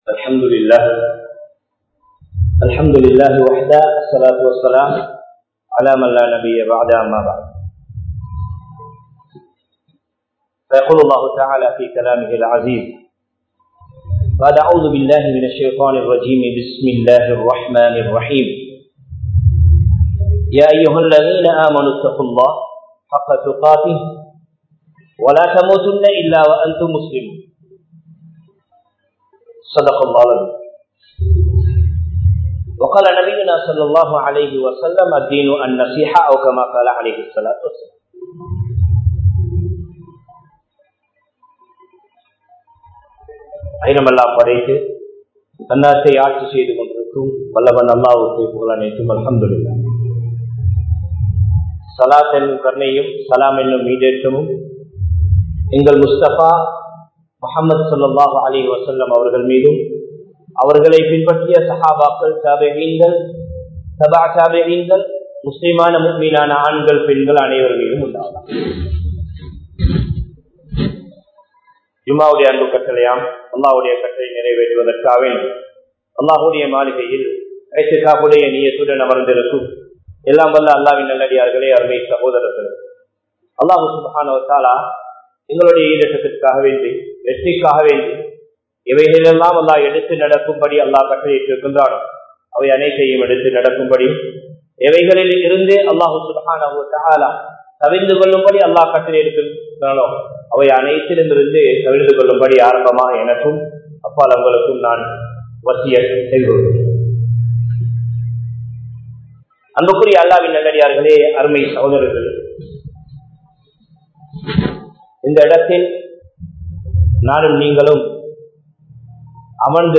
Ramalanai Varaveatpoam (ரமழானை வரவேற்போம்) | Audio Bayans | All Ceylon Muslim Youth Community | Addalaichenai
Maskeliya Jumua Masjidh 2018-05-11 Tamil Download